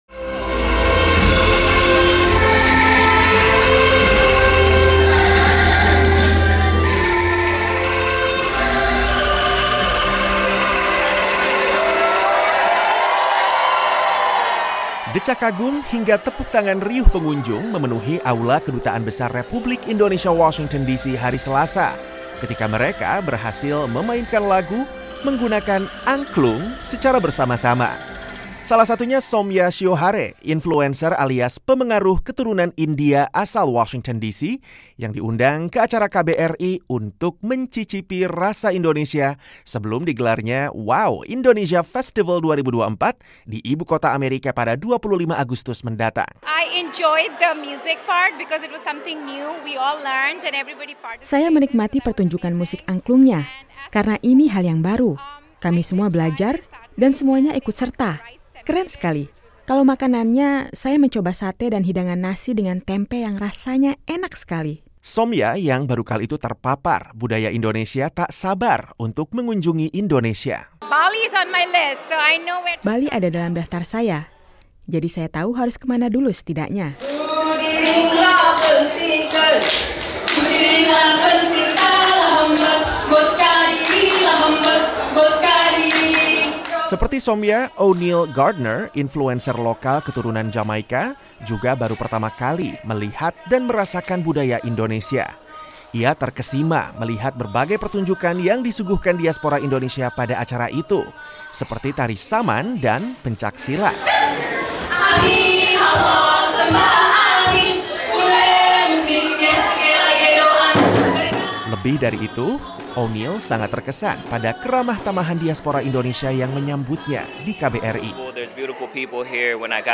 Decak kagum hingga tepuk tangan riuh pengunjung memenuhi aula Kedutaan Besar Republik Indonesia Washington DC, Selasa (13/8), ketika mereka berhasil memainkan lagu menggunakan angklung secara bersama-sama.